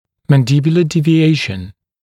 [ˌmæn’dɪbjulə ˌdiːvɪ’eɪʃn][ˌмэн’дибйулэ ˌди:ви’эйшн]отклонение нижней челюсти, девиация нижней челюсти